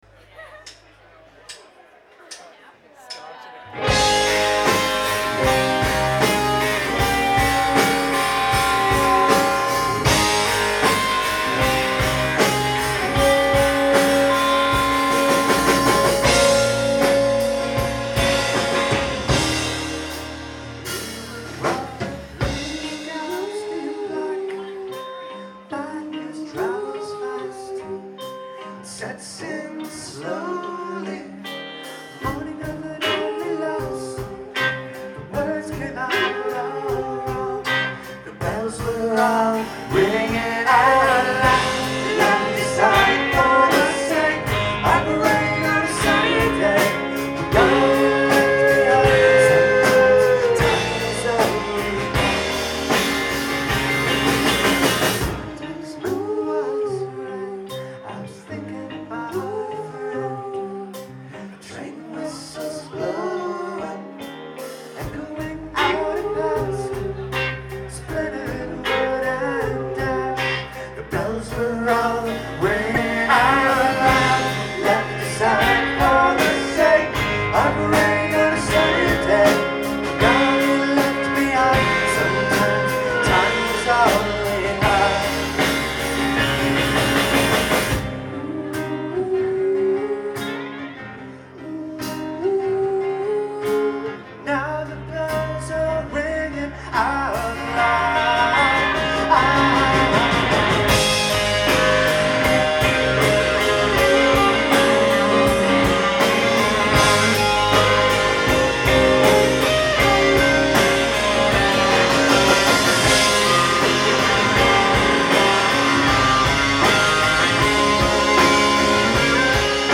Live at Great Scott